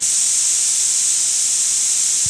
SPRAY CAN 1.WAV